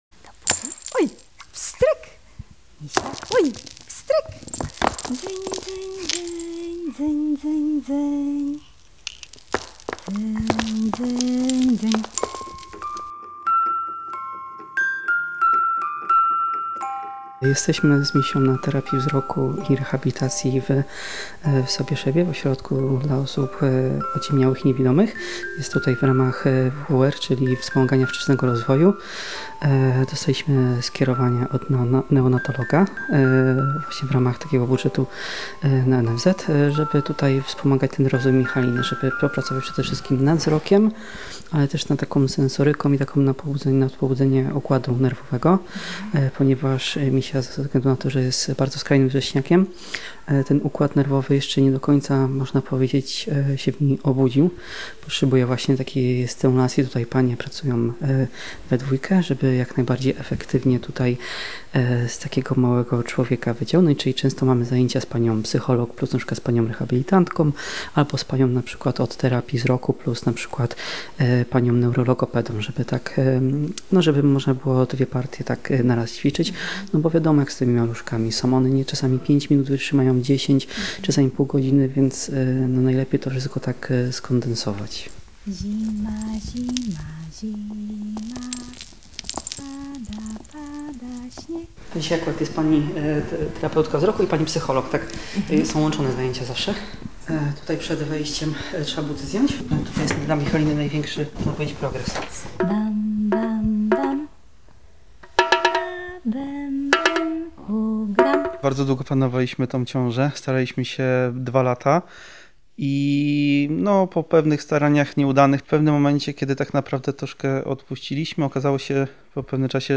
Reportaż „315 gramów”. Wzruszająca historia najmniejszego dziecka, jakie urodziło się w Polsce